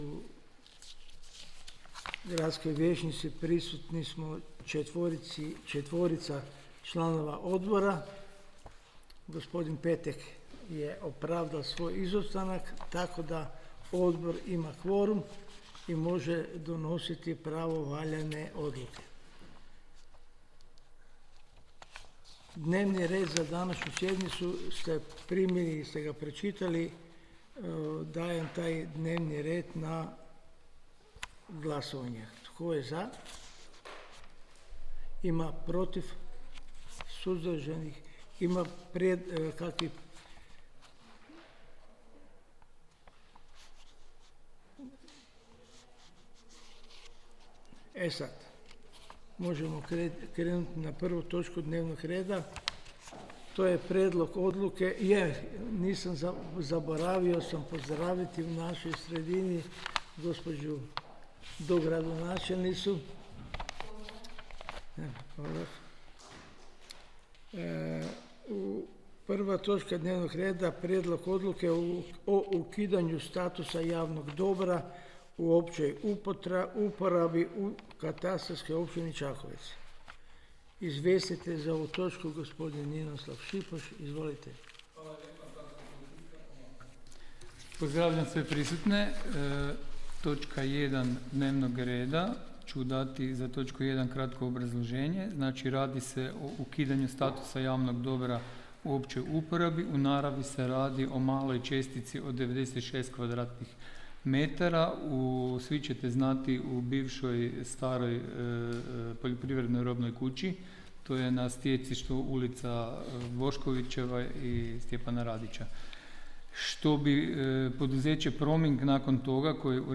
Obavještavam Vas da će se 2. sjednica Odbora za Statut, Poslovnik i normativnu djelatnost Gradskog vijeća Grada Čakovca održati dana 6. listopada 2025. (ponedjeljak), u 10:00 sati, u prostorijama Uprave Grada Čakovca.